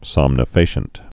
(sŏmnə-fāshənt)